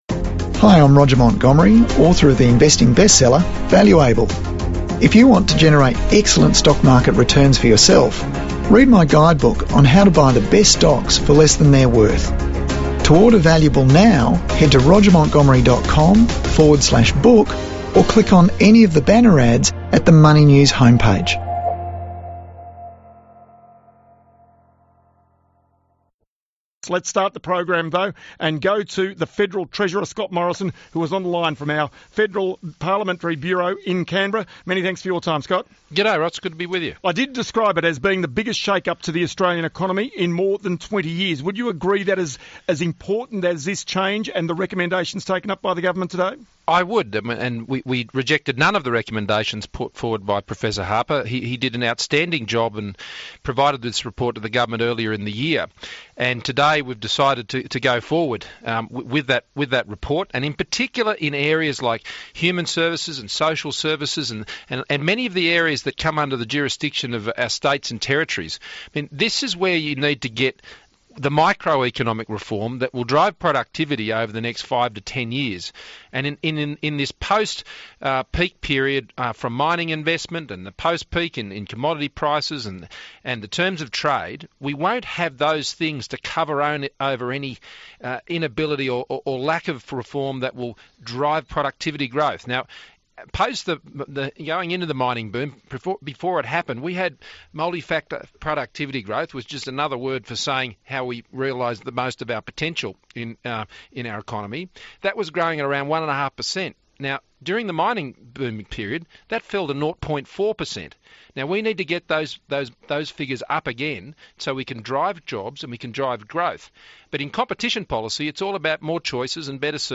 Ross Greenwood speaks to Treasurer Scott Morrison about the biggest shake-up to the economy in 20 years with the Government taking up 44 or the 56 recommendations from the Harper Competition Review